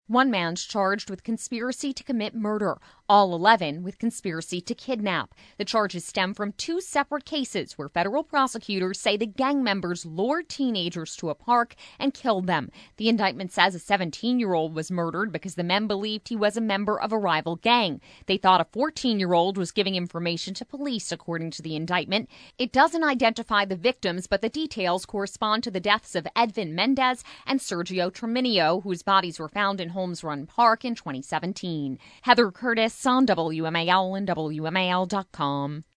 FRI-PM-MS-13-Voicer-2.mp3